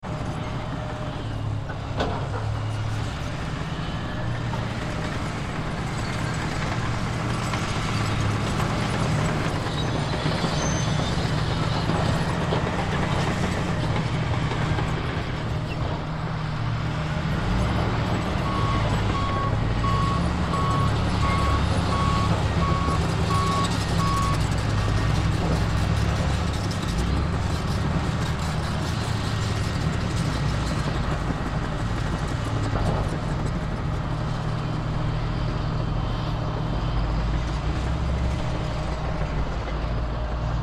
Звуки бульдозера
В процессе работы